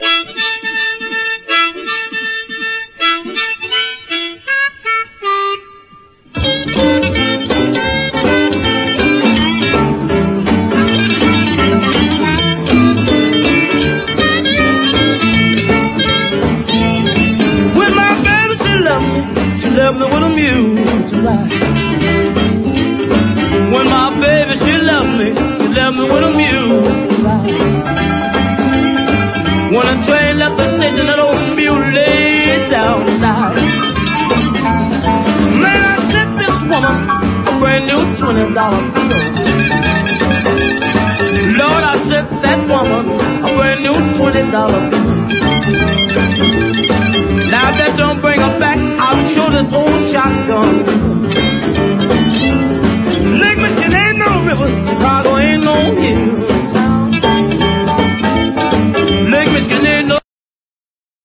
EASY LISTENING / OTHER / BLUES / RHYTHM & BLUES
名演多数のウエスト・コースト・ブルース/R&B集！